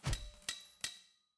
Build Tower.mp3